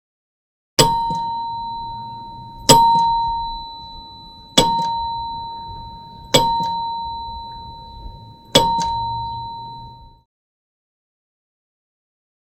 Bell ( Interior ); Close Up On Tram Bell With Off Screen Traffic Sounds.